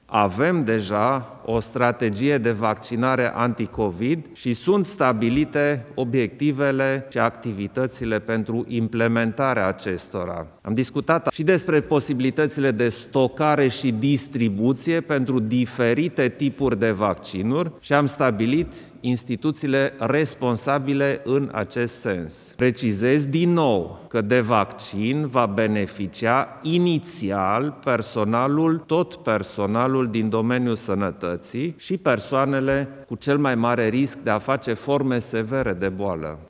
Șefa Executivului European punctează că deja se lucrează cu statele membre pentru a pregăti campanii de vaccinare –  lucru subliniat și de preșdintele Klaus Iohannis în ultima conferință de presă.